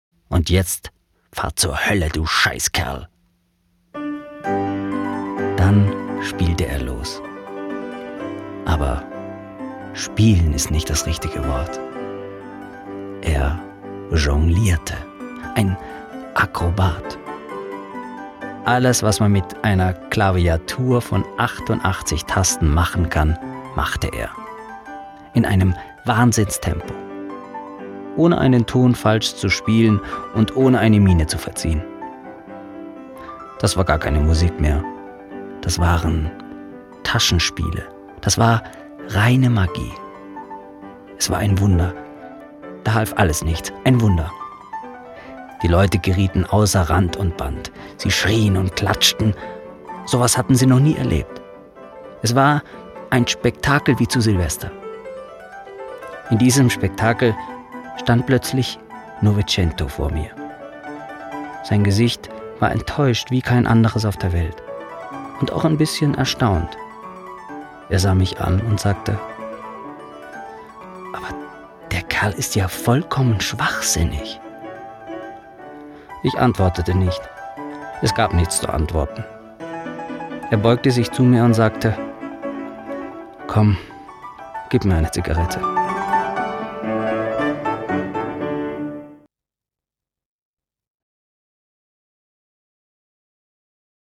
deutscher Sprecher und Schauspieler. Mitteltiefe bis tiefe Stimmlage.
Sprechprobe: Industrie (Muttersprache):
voice over talent german